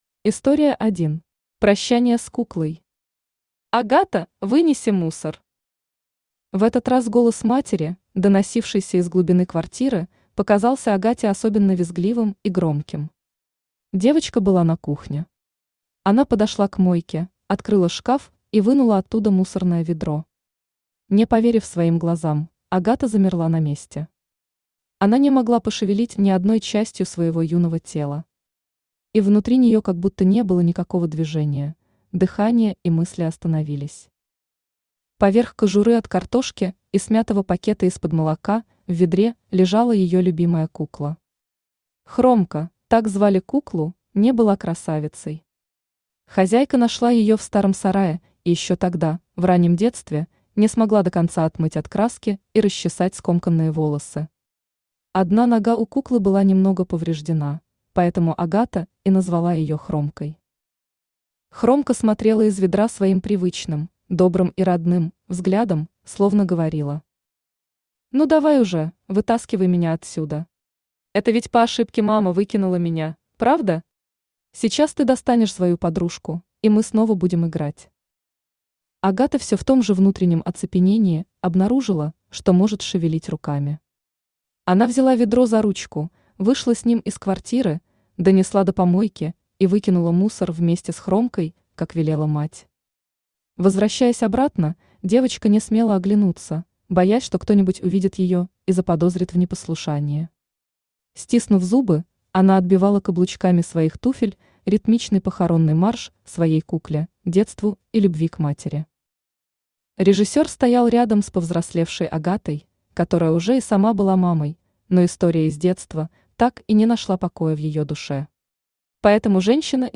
Сборник Автор Елена Викторовна Малинина Читает аудиокнигу Авточтец ЛитРес.